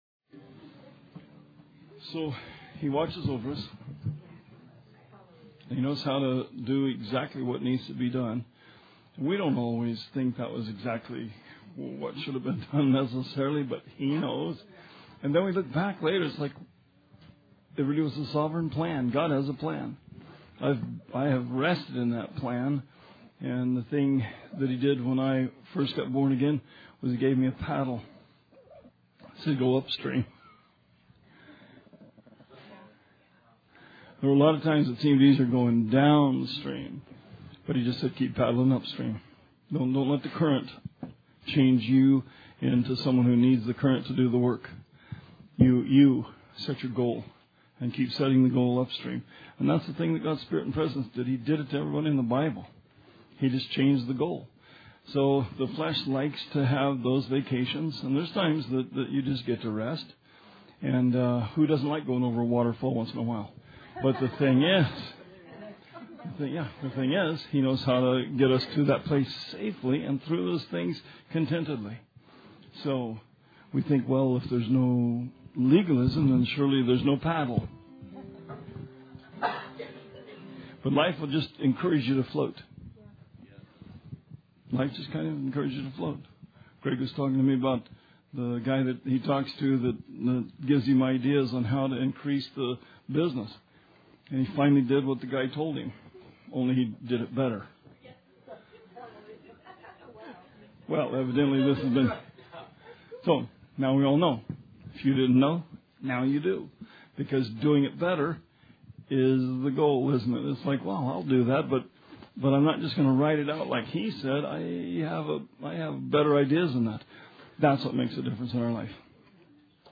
Bible Study 1/25/17